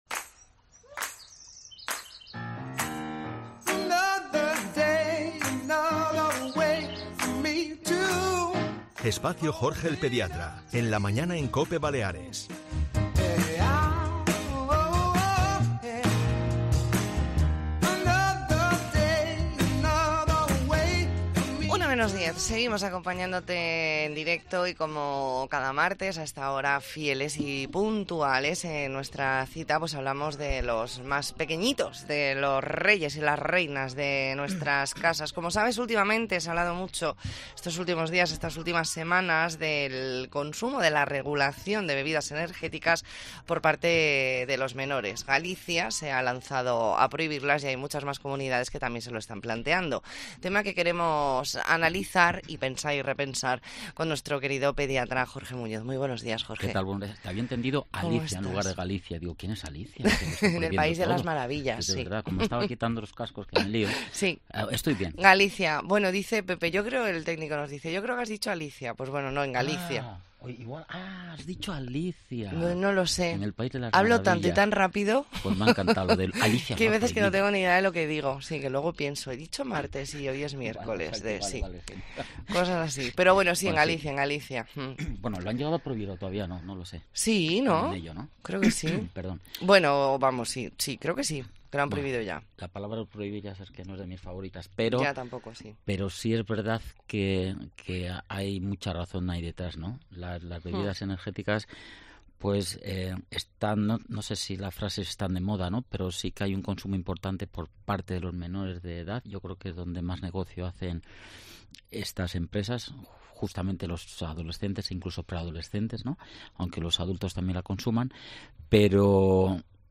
Hoy nos explica por qué las bebidas energéticas son peligrosas para los menores. Entrevista en La Mañana en Baleares, martes 24 de octubre de 2023.